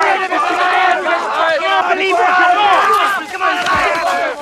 • GROUP YELLING.wav
GROUP_YELLING_XDt.wav